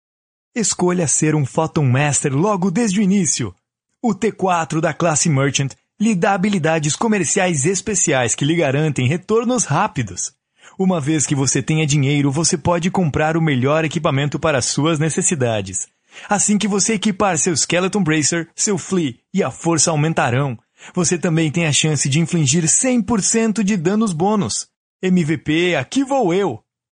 外籍英式英语配音
配音风格： 自然